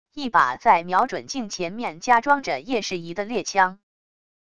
一把在瞄准镜前面加装着夜视仪的猎枪wav音频